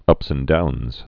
(ŭps ən dounz)